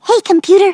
synthetic-wakewords
ovos-tts-plugin-deepponies_Fluttershy_en.wav